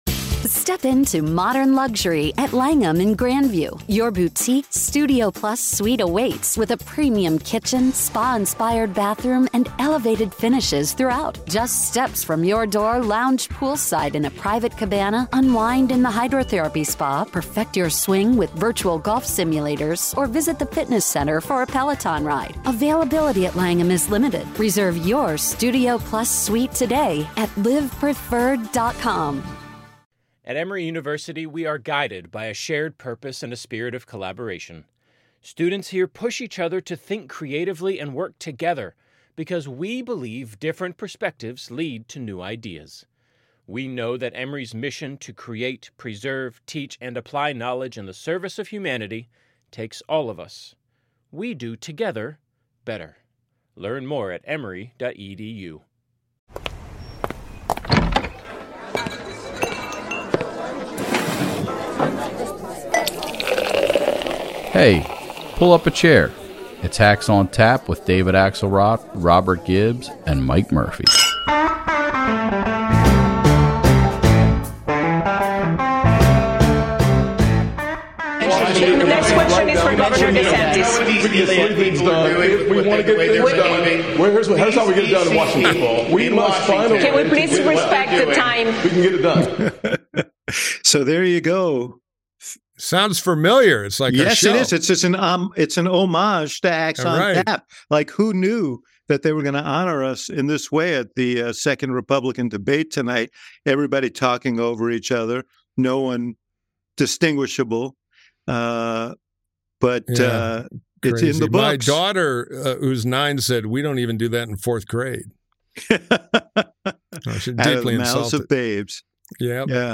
Facebook Twitter Headliner Embed Embed Code See more options Late last night, despite all three Hacks feeling a little bit dumber, the guys convened to record a special post debate Hacks on Tap. The Hacks declare their debate winners and losers, and predict who will be on the next debate stage. They also break down Newsom’s new strides, picket line politics, flip floppery, and battery powered boats.